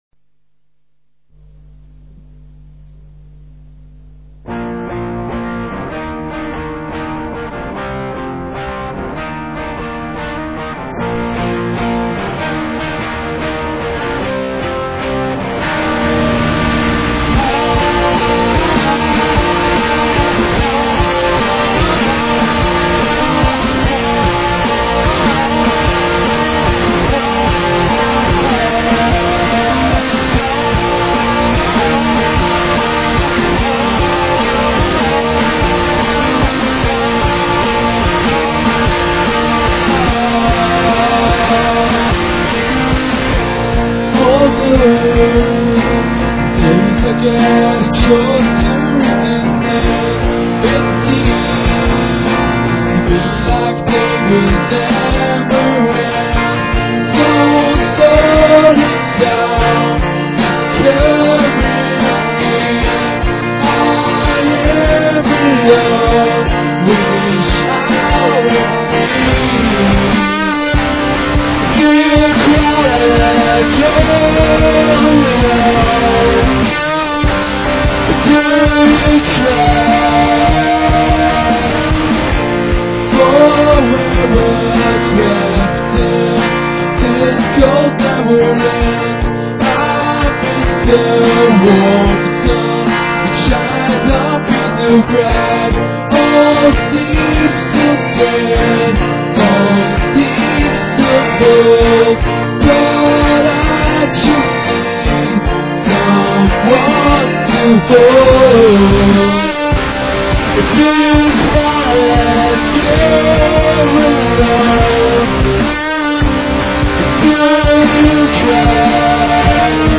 4-track-recording
(485kb, 16kbps - Mono)